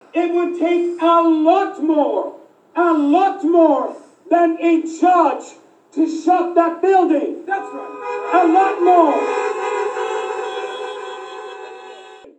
After welcoming those in attendance, he urged them to act as if they were at Costco or Walmart.